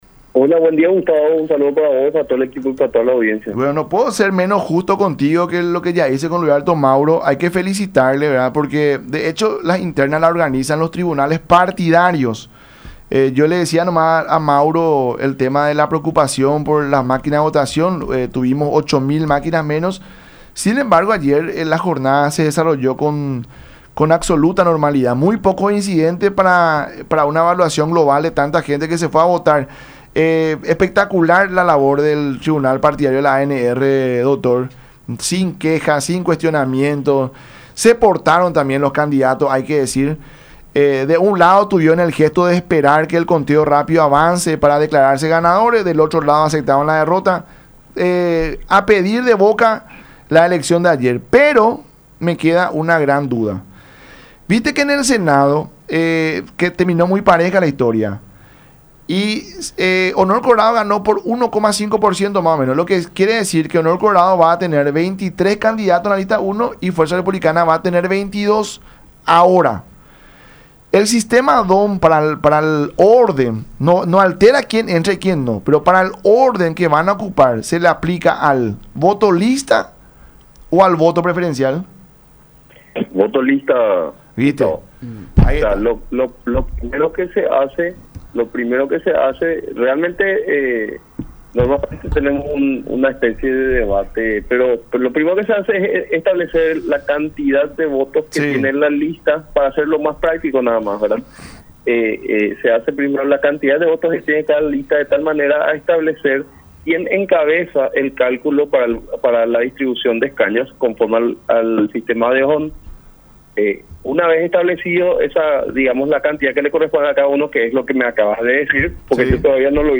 en diálogo con La Mañana De Unión por Unión TV y radio La Unión.